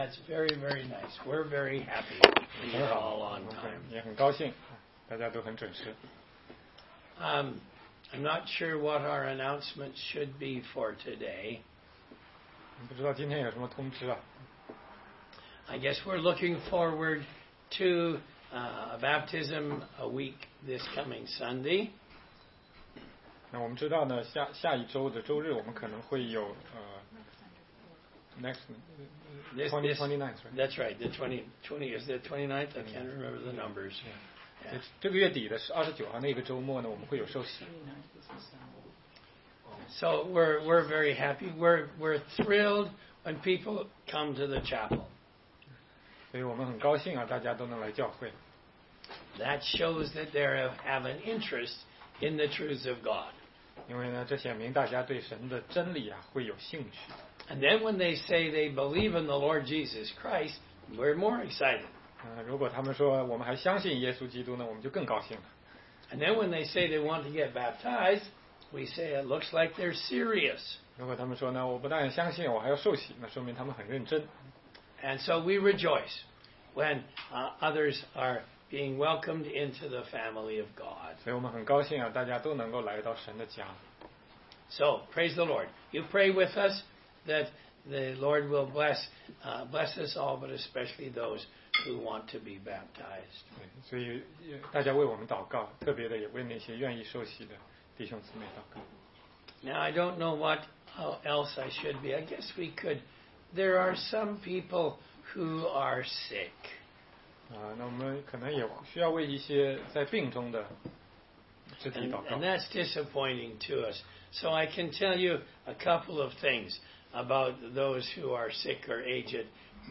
16街讲道录音 - 哥林多前书5章